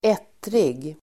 Ladda ner uttalet
Folkets service: ettrig ettrig adjektiv, hot-tempered , irascible Uttal: [²'et:rig] Böjningar: ettrigt, ettriga Synonymer: ilsken Definition: envis och ilsken hot-tempered adjektiv, hetlevrad , ettrig